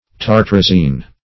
Search Result for " tartrazine" : The Collaborative International Dictionary of English v.0.48: Tartrazine \Tar"tra*zine\, n. [Tartaric + hydrazine.]